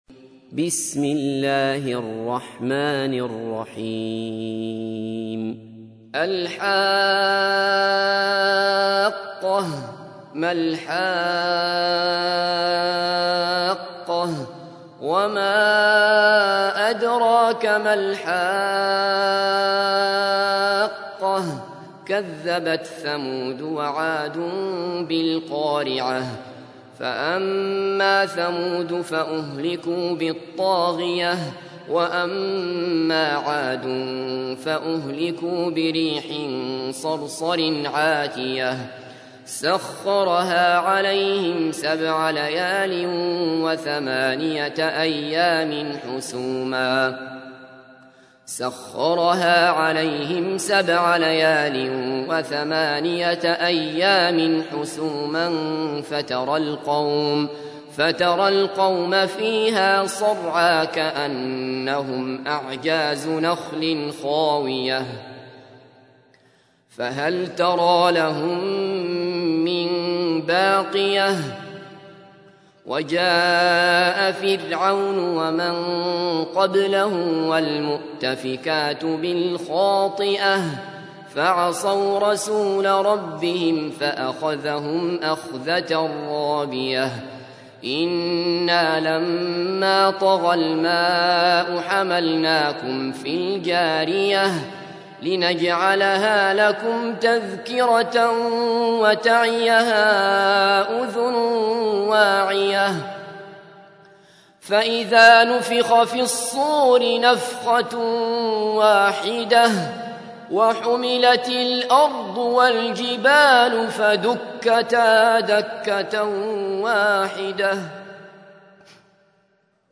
تحميل : 69. سورة الحاقة / القارئ عبد الله بصفر / القرآن الكريم / موقع يا حسين